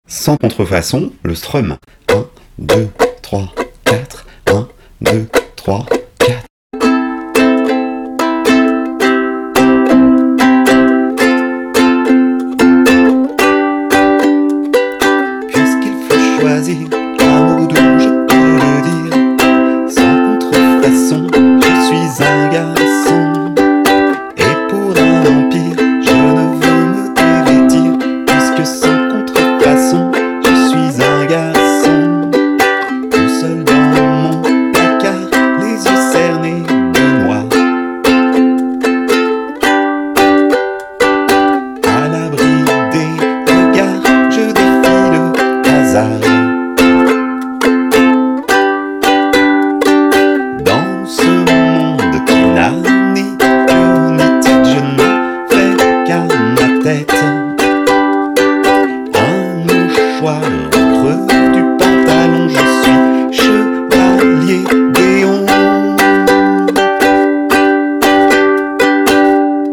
Voici le strum joué un peu moins lentement (110 bpm) :